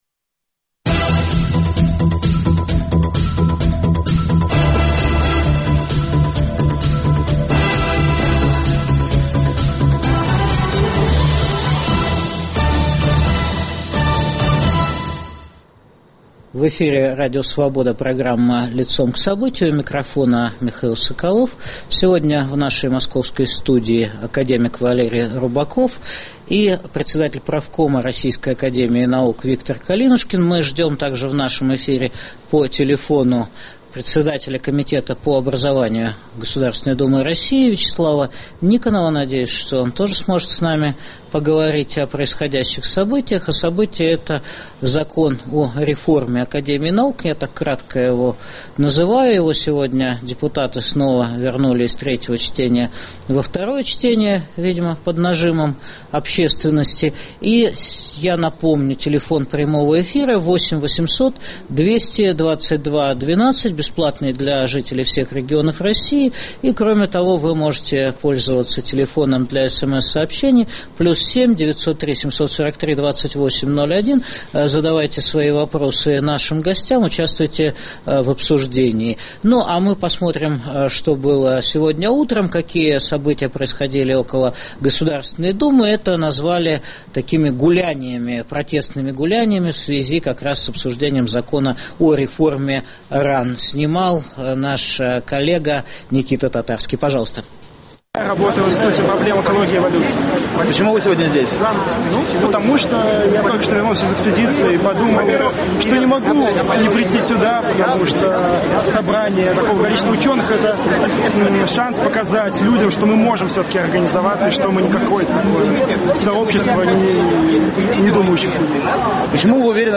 Кто и зачем уничтожает Академию наук? В программе по итогам заседания Государственной думы РФ дискутируют